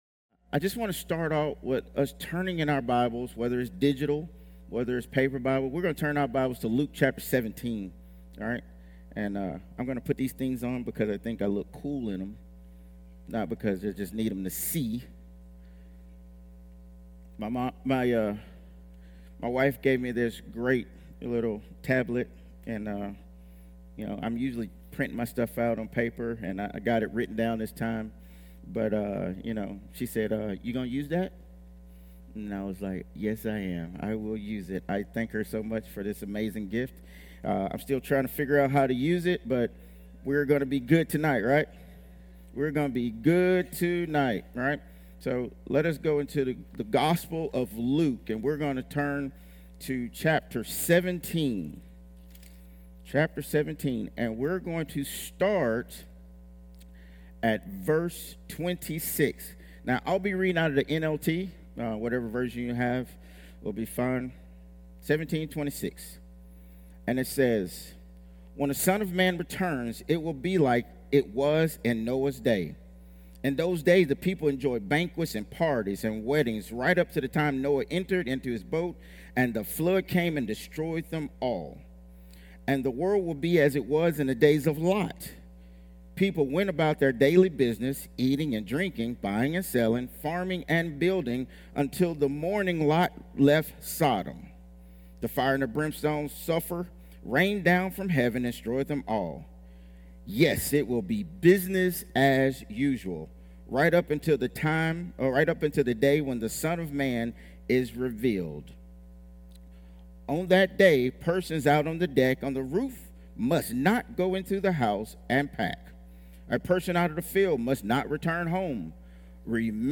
Sunday and Wednesday sermons from Glory To Him Church in Ozark, AL.